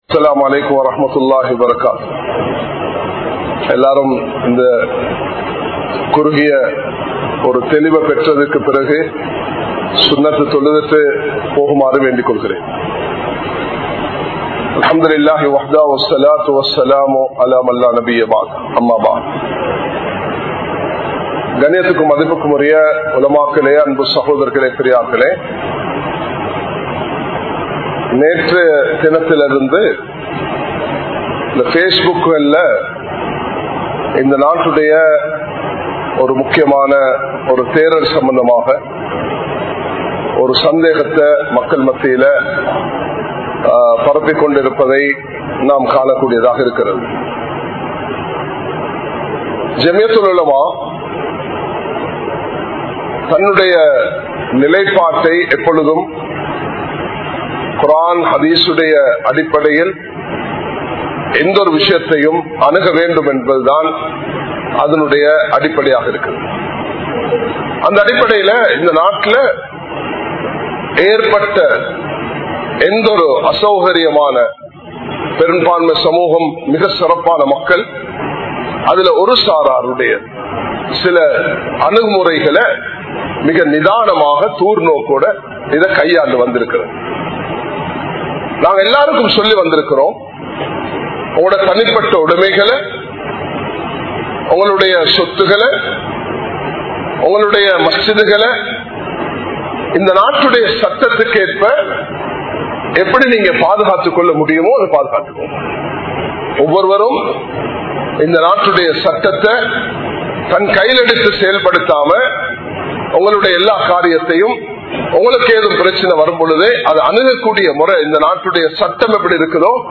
Special Announcement | Audio Bayans | All Ceylon Muslim Youth Community | Addalaichenai